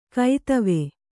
♪ kaitave